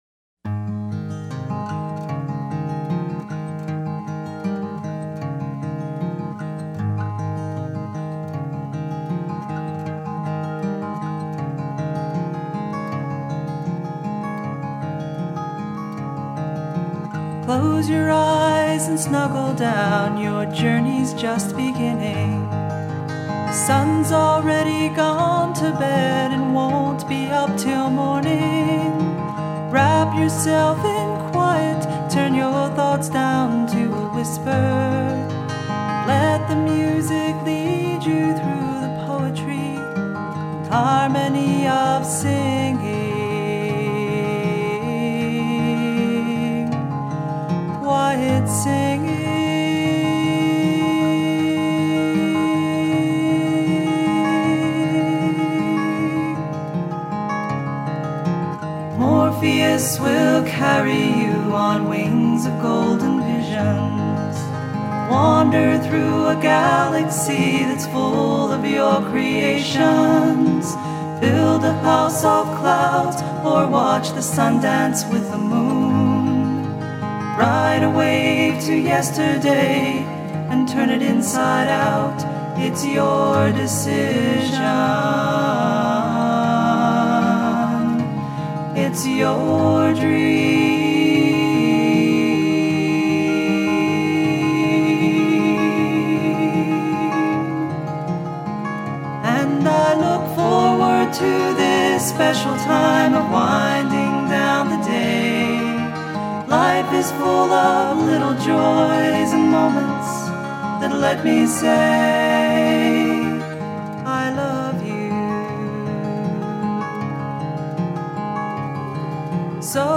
lullaby
(caution, don’t listen while driving)